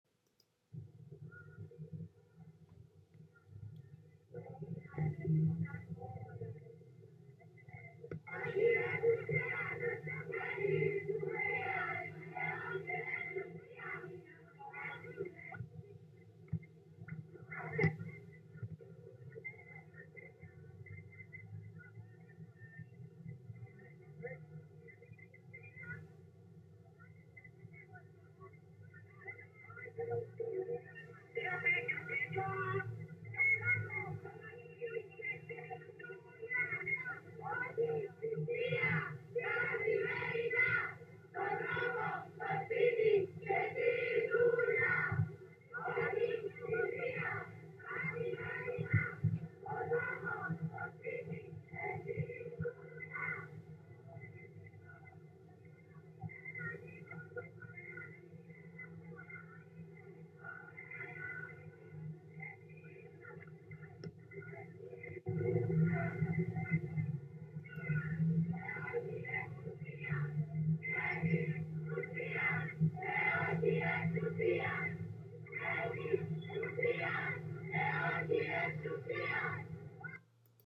Στο ψηφιοποιημένο αρχείο της ΕΡΤ υπάρχει μόνο μία σχετική ηχητική καταγραφή που μάλλον προέρχεται από μία διαδήλωση που πραγματοποιήθηκε στις 6 Οκτωβρίου 1980 στα Προπύλαια με κάλεσμα της ‘Επιτροπής Αγώνα για την Αλλαγή του Οικογενειακού Δικαίου’.
1981-demo-soundscape.m4a